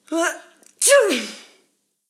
Mujer estornudando
estornudar
mujer
Sonidos: Acciones humanas
Sonidos: Voz humana